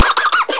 cough.mp3